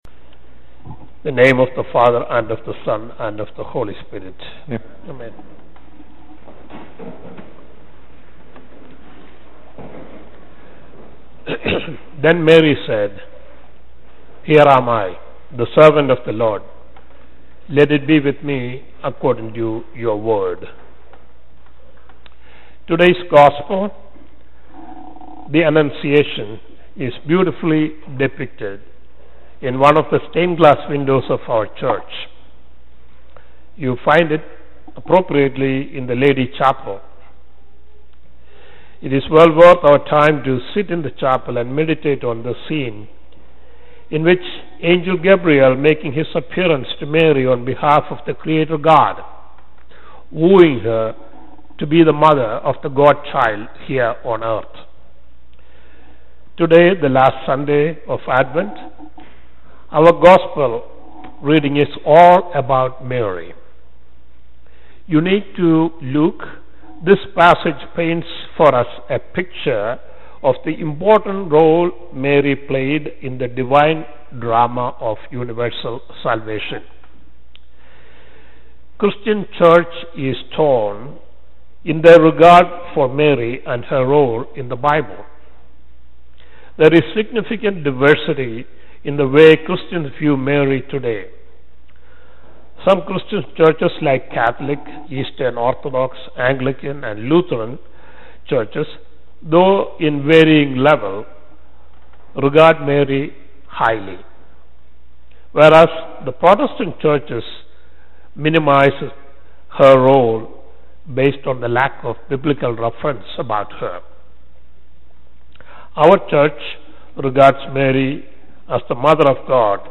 Saint Peter's Episcopal Church :: Phoenixville, PA
Sermon